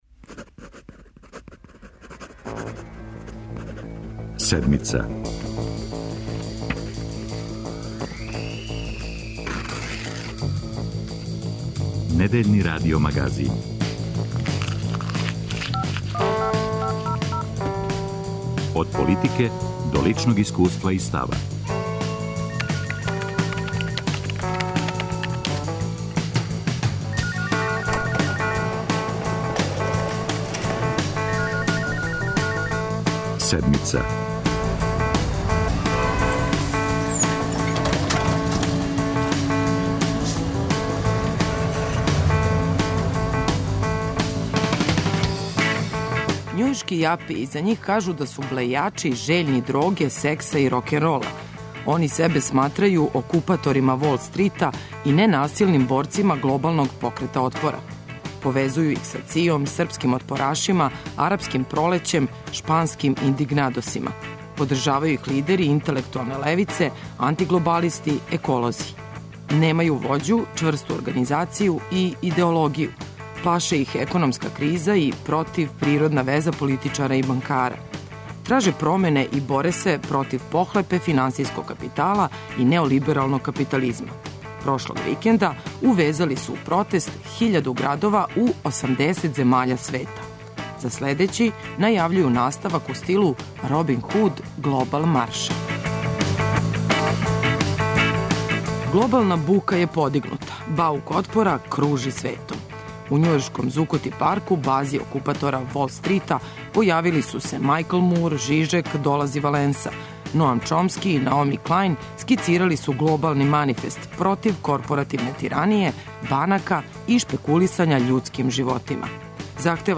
Репортер Седмице био је код њих.